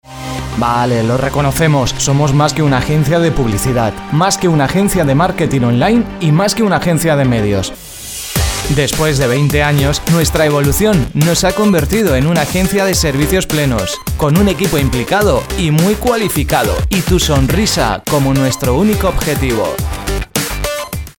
Sprechprobe: Werbung (Muttersprache):
Durante 25 años en el mundo de la radio mi voz ha pasado por tooodos los registros que te puedas imaginar, los más serios, divertidos, tensos, tontos... todo ello plasmado en cuñas de radio, spots de Tv, programas musicales, de noticias, como reportero en unidad móvil, en presentación de eventos y charlas, en definitiva una voz versátil preparada para ser modulada y adaptada a cualquier trabajo